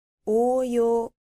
• おうよう
• ouyou